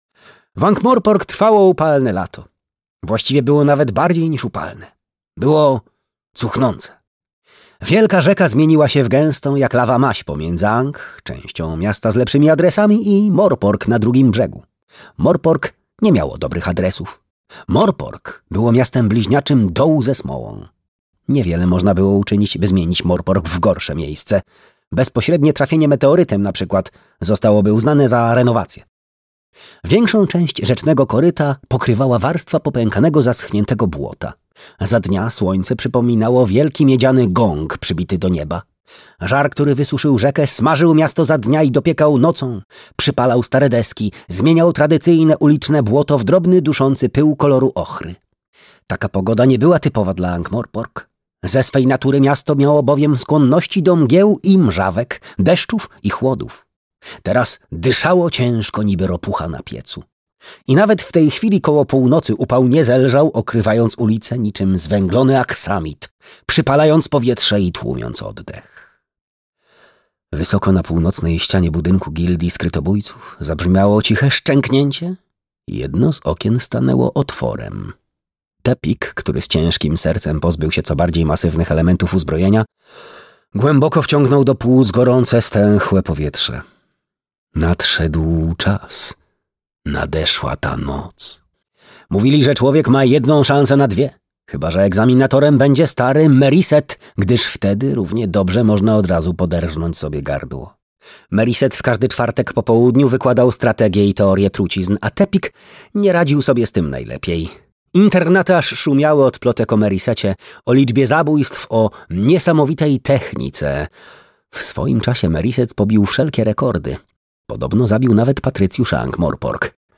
Piramidy - Terry Pratchett - audiobook